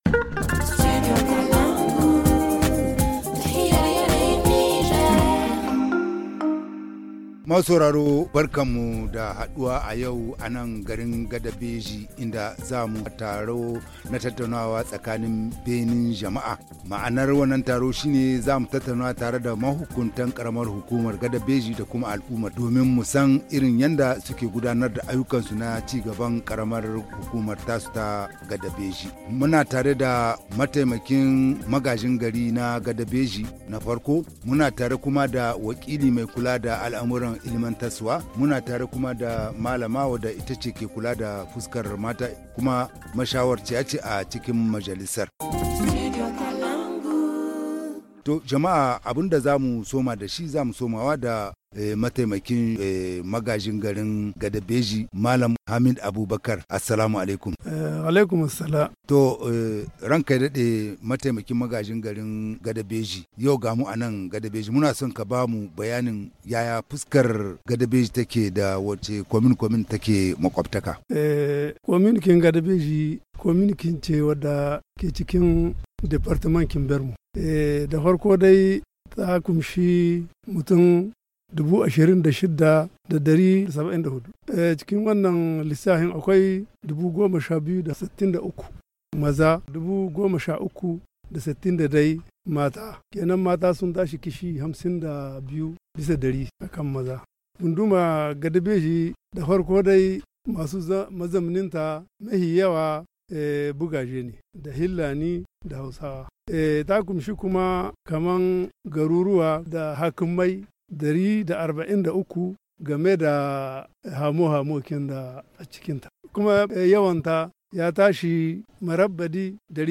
Le forum de ce jour est délocalisé dans la commune rurale de Gadabédji département de Bermo.
Le 22 aout 2022, le Studio Kalangou a initié un débat public dans le chef-lieu de la commune pour donner la parole aux citoyens et aux autorités communales et coutumières afin de discuter et de débattre sur les différentes préoccupations des populations. Le thème du forum est Redevabilité de la gouvernance des élus locaux. Plusieurs thématiques ont été abordées au cours de ce débat dans un cadre démocratique et ouvert en présence de la population